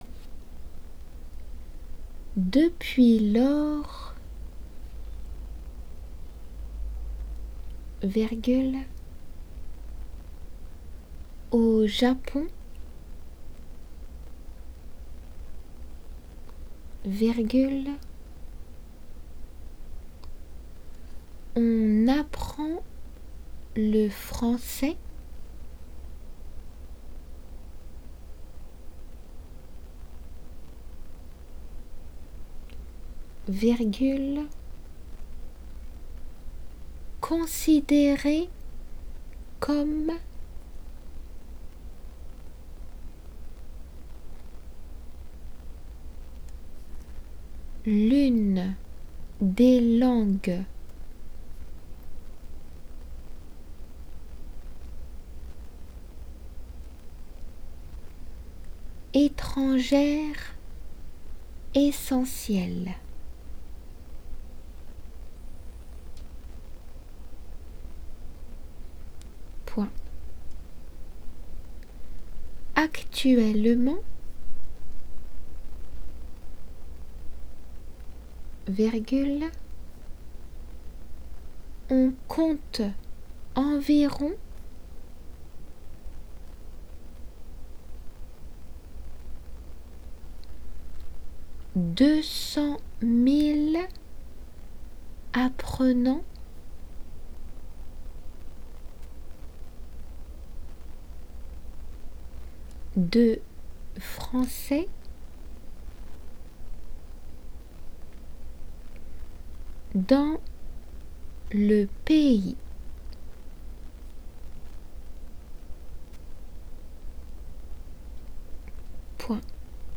仏検デイクテ―音声–1
デクテの速さで